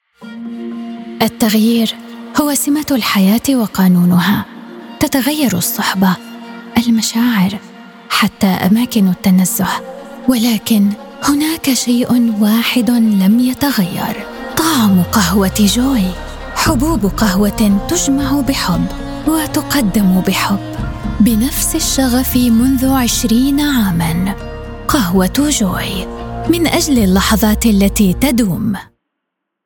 Never any Artificial Voices used, unlike other sites.
Adult (30-50)